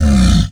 ANIMAL_Tiger_Growl_02.wav